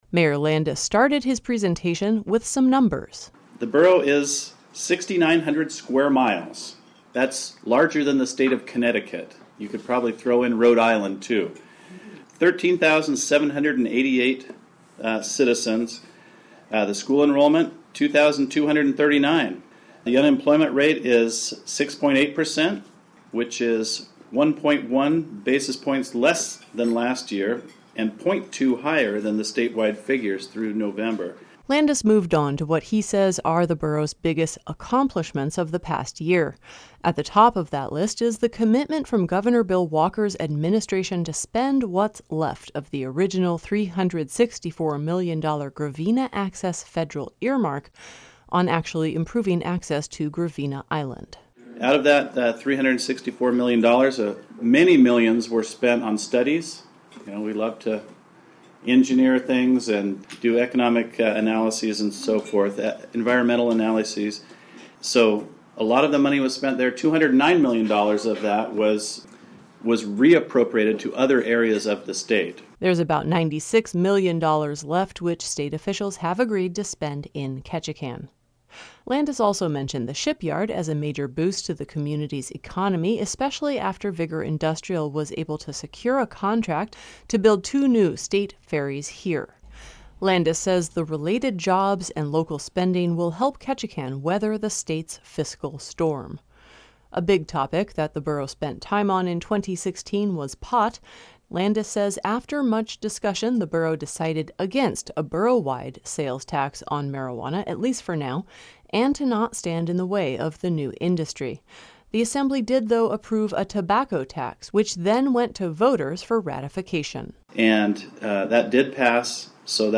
Ketchikan Gateway Borough Mayor David Landis gave his “state of the borough” address to a Chamber of Commerce audience on Wednesday.
After Landis’ presentation, he took questions from the audience.